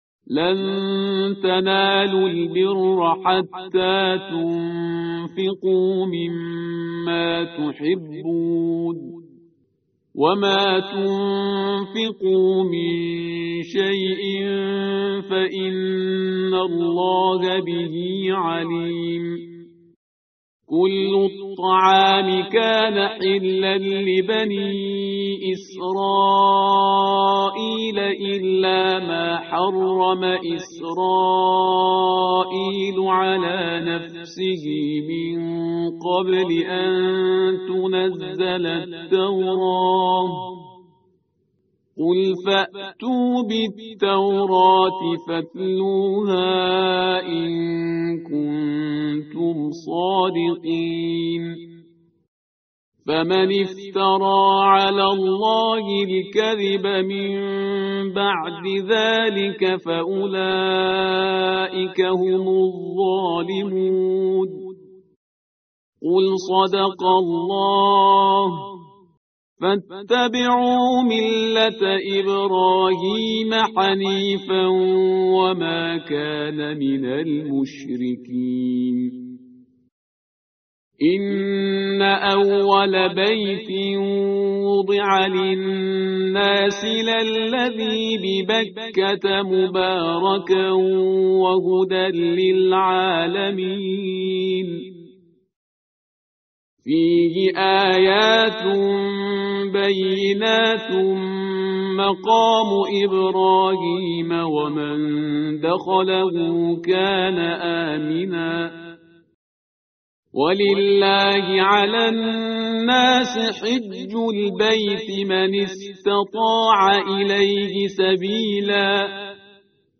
متن قرآن همراه باتلاوت قرآن و ترجمه
tartil_parhizgar_page_062.mp3